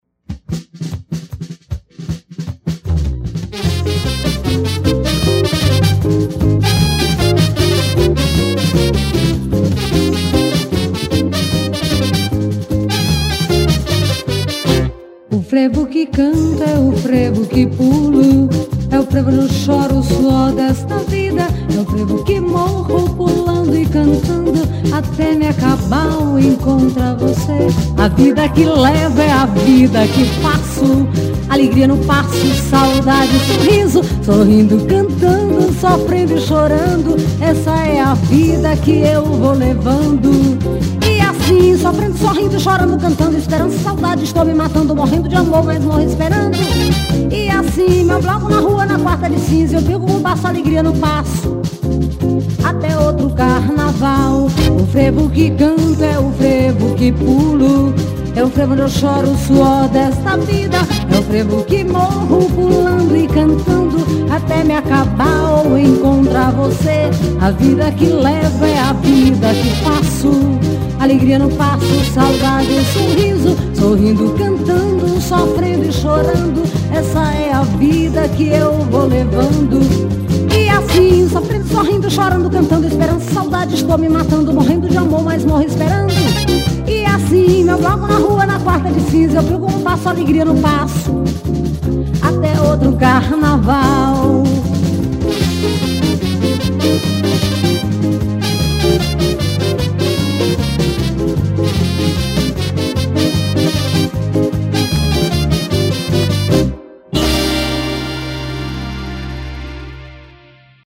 106      Faixa:     Frevo